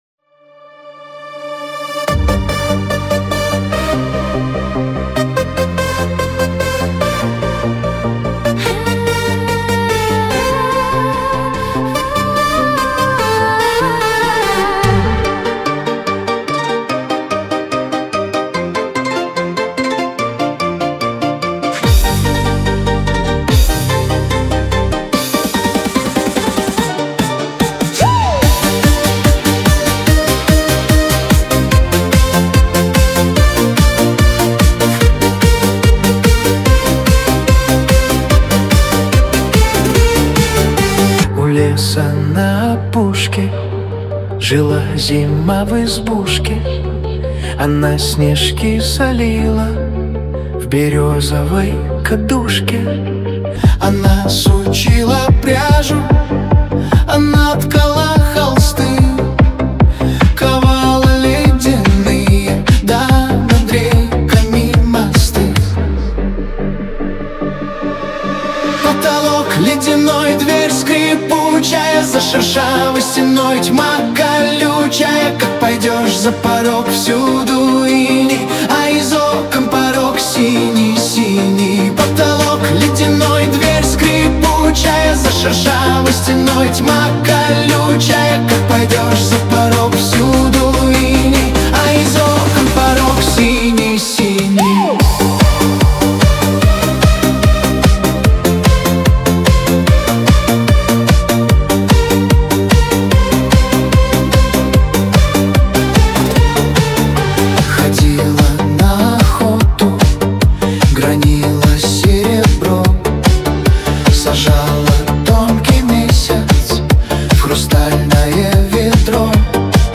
Динамичная музыка